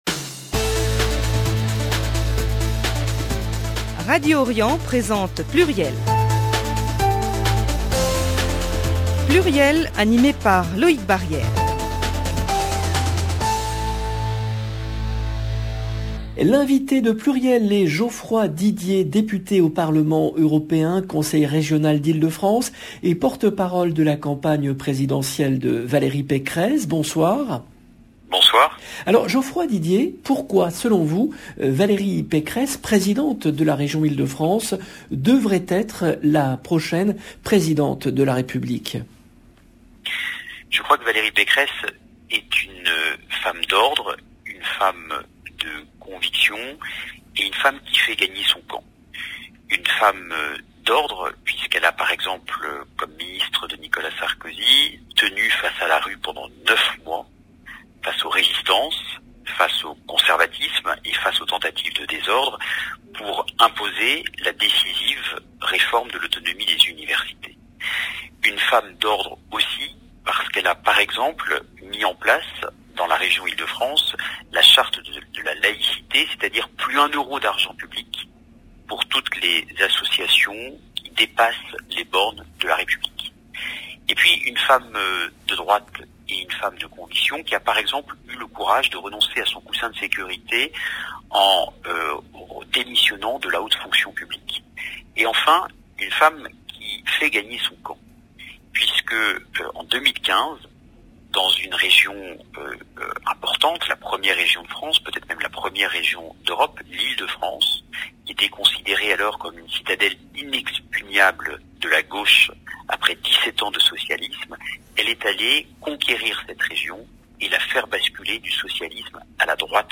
L’invité de PLURIEL est Geoffroy Didier , député au Parlement européen, Conseiller régional d’Ile-de-France et porte-parole de la campagne présidentielle de Valérie Pécresse